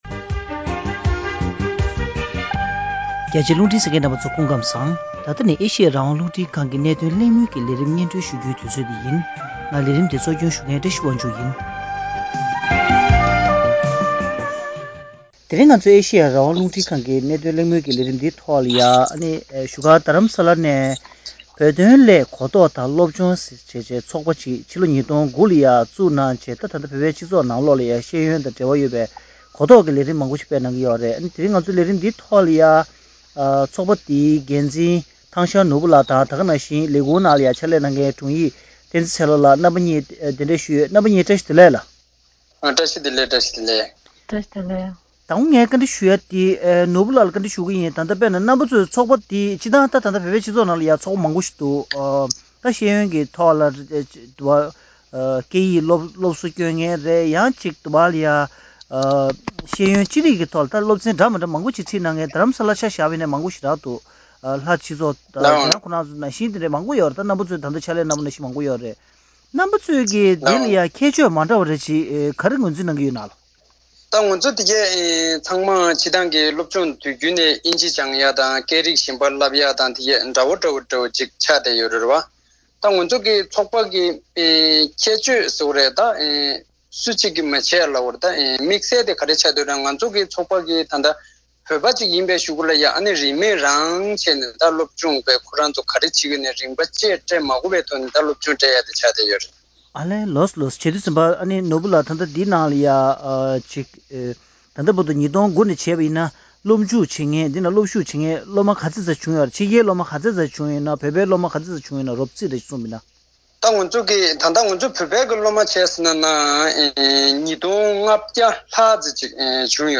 བཞུགས་སྒར་རྡ་རམ་ས་ལར་ཆགས་པའི་བོད་དོན་སླད་གོ་རྟོགས་དང་སློབ་སྦྱོང་ཞེས་པའི་ཚོགས་པའི་ལས་དོན་དང་གྲུབ་འབྲས་སྐོར་གླེང་མོལ་ཞུས་པ།